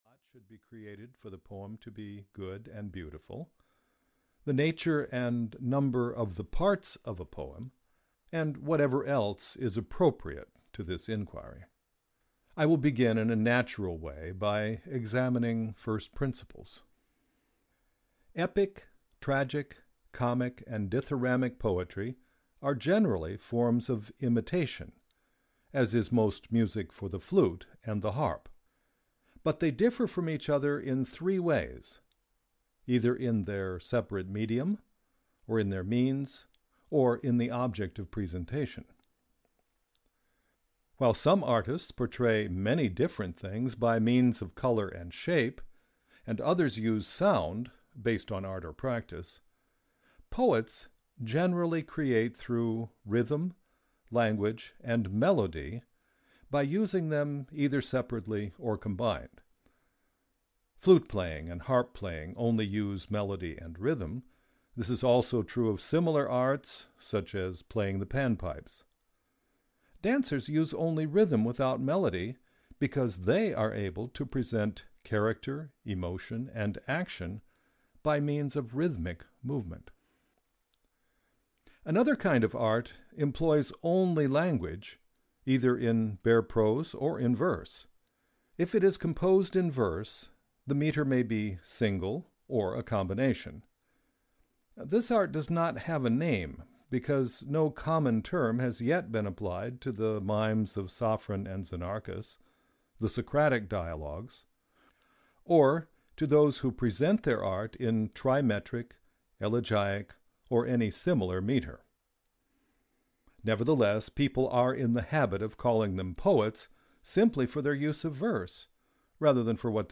Aristotle’s Poetics (EN) audiokniha
Ukázka z knihy